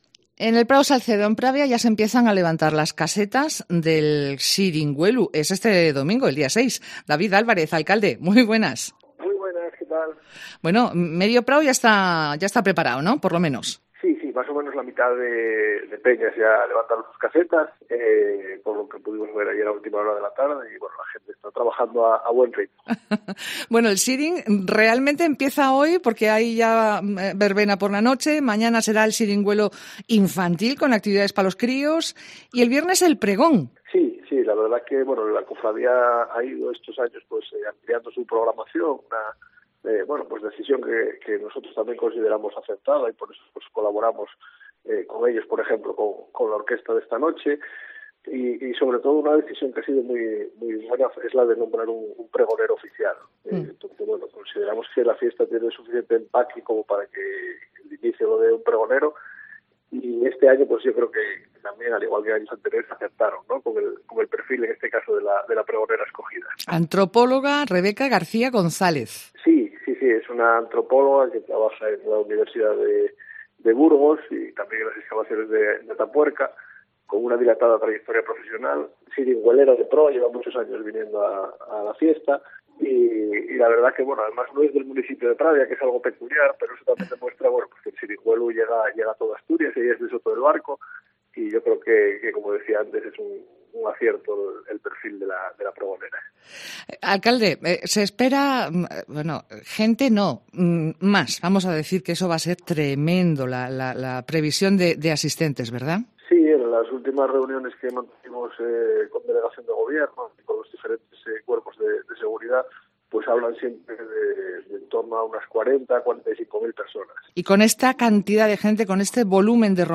Entrevista con David Álvarez, alcalde de Pravia